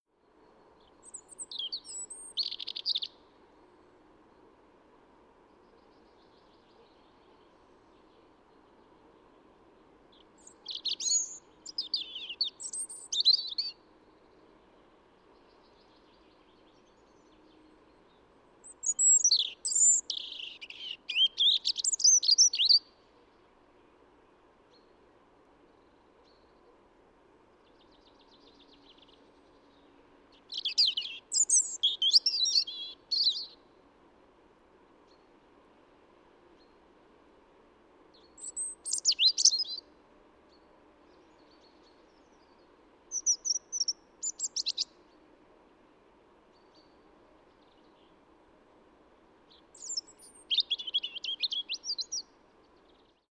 Punarind       Erithacus rubecula
Punarinna laulu meeldivust on ülistanud nii ornitoloogid, kui luuletajad hoolimata sellest, et laulul puuduvad selgepiirilised stroofid. Laul algab ja lõpeb äkitselt.
Punarinna laul:
punarind_laul-pikk.mp3